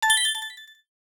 Sound effect of "Coin Small Group Appear" in Super Mario 3D Land
SM3DL_Coin_Small_Group_Appear.oga